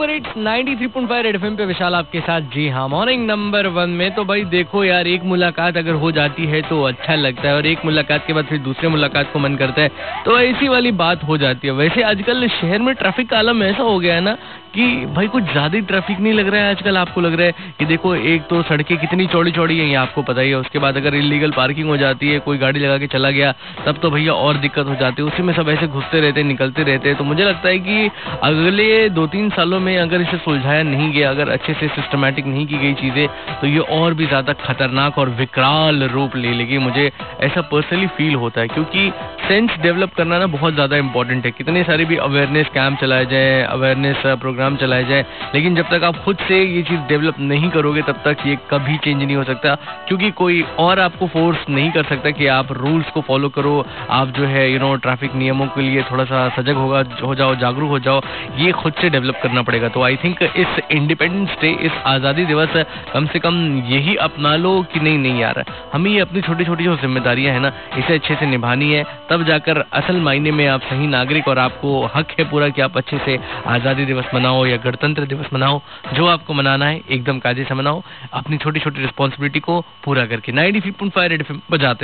Rj About Indian citizen Responsibility